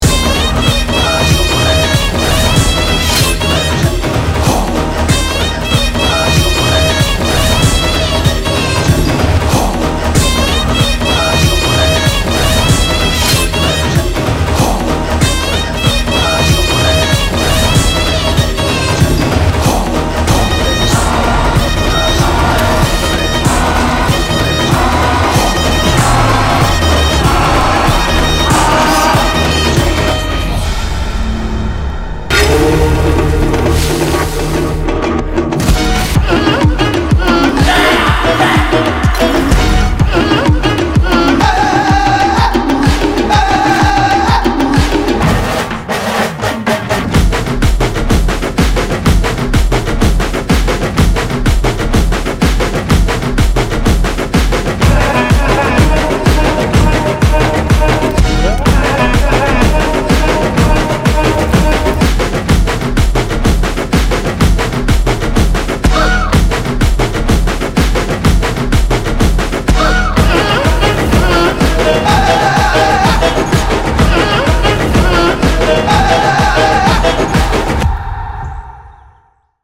removed disturbances to the maximum possibility.